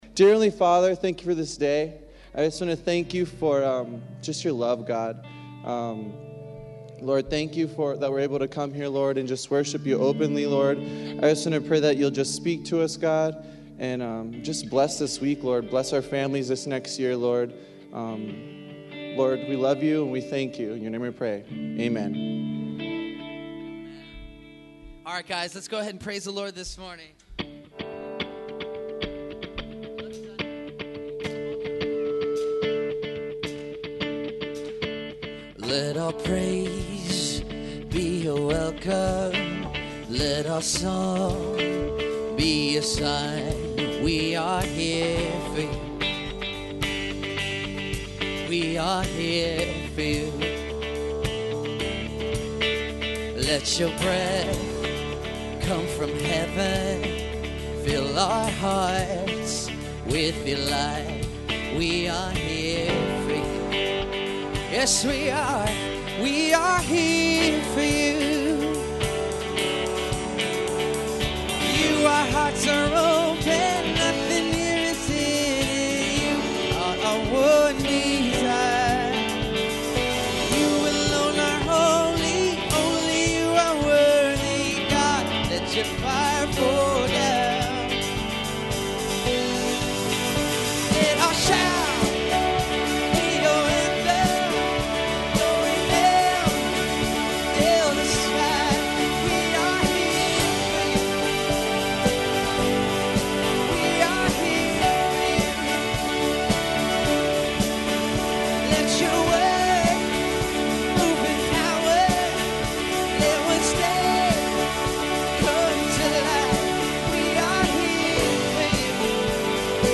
Thanksgiving Service 2015 - Calvary Tucson Church
Holiday Message